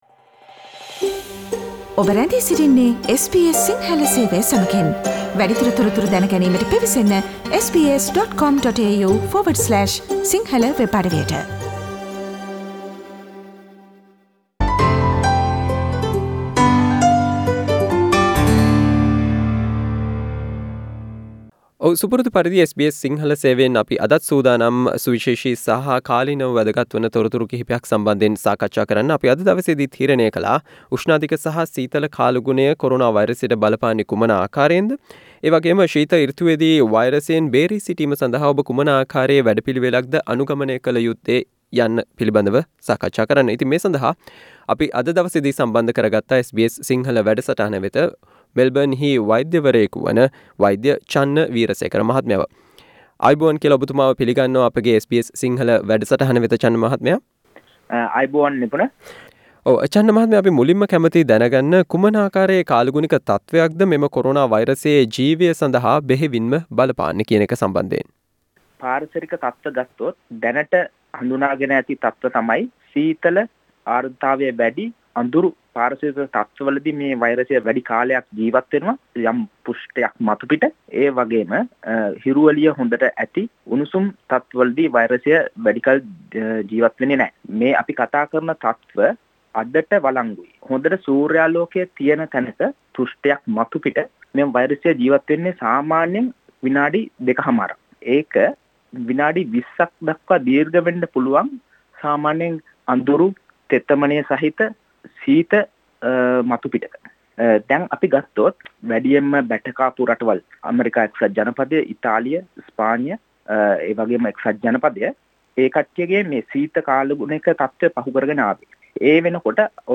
SBS sinhala interview